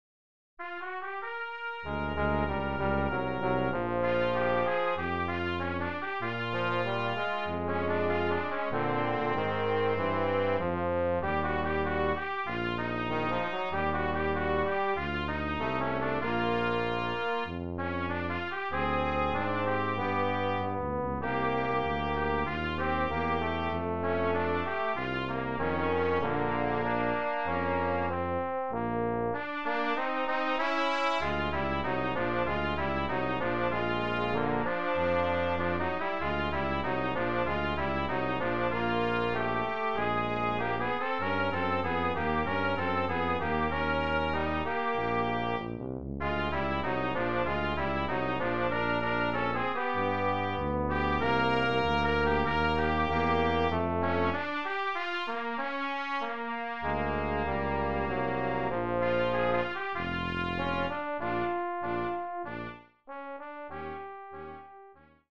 Brass Trio TTT